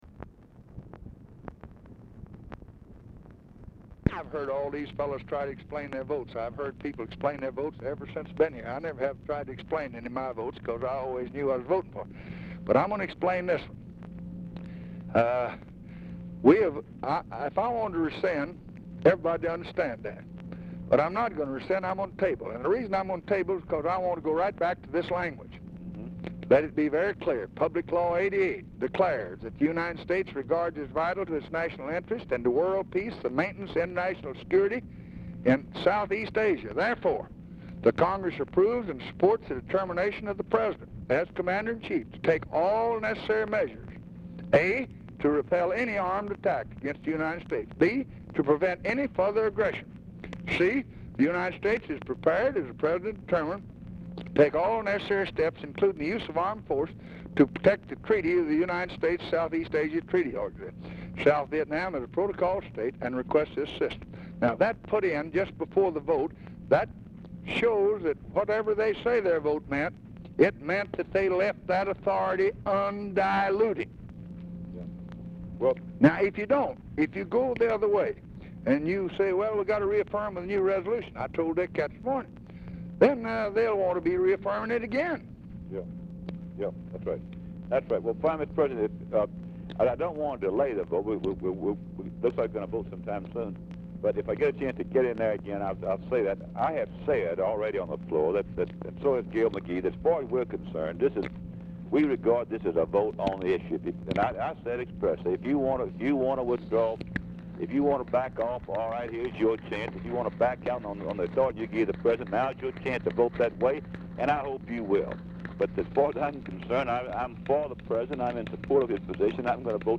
Telephone conversation # 9807, sound recording, LBJ and RUSSELL LONG, 3/1/1966, 2:40PM | Discover LBJ
Format Dictation belt
Location Of Speaker 1 Oval Office or unknown location
Specific Item Type Telephone conversation